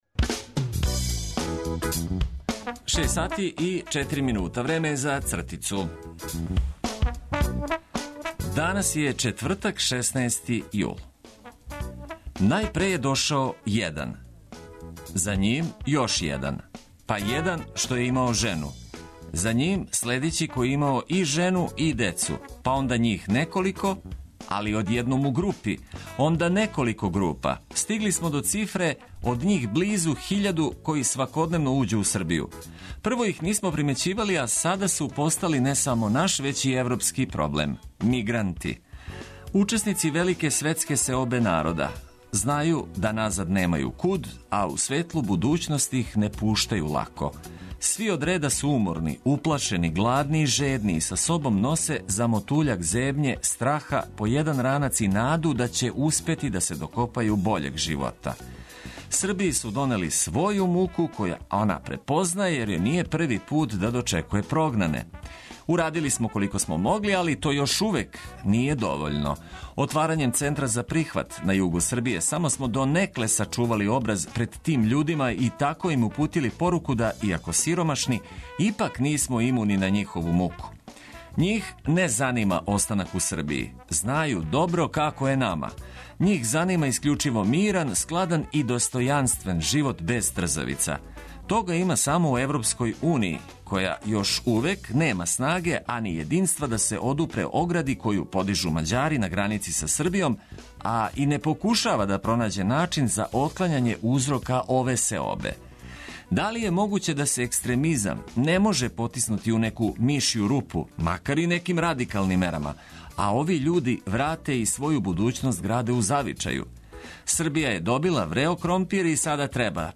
Нека дан почне уз добру музику коју ћемо прошарати информацијама од користи за започињање новог дана.